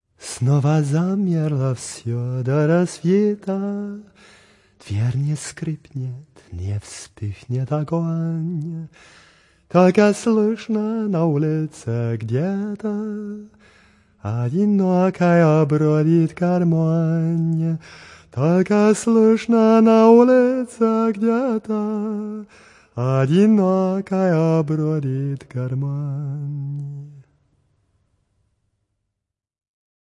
Russian Song II: